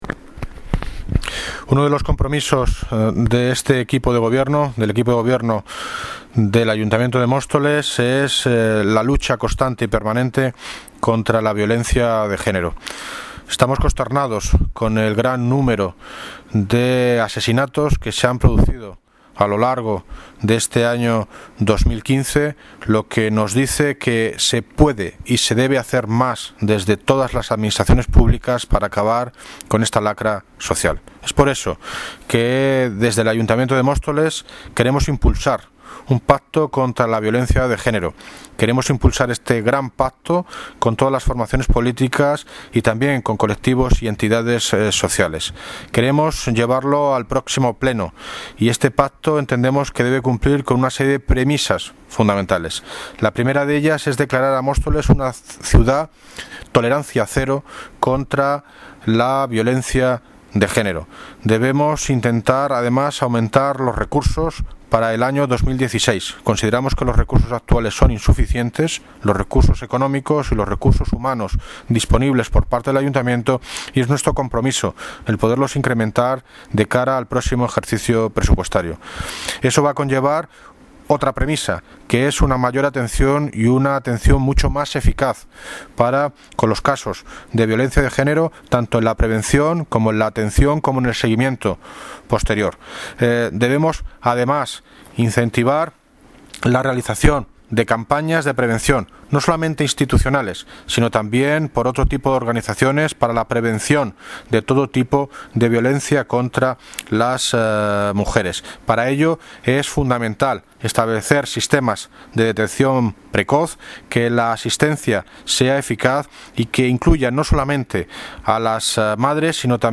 Audio - David Lucas (Alcalde de Móstoles) sobre pacto contra violencia machista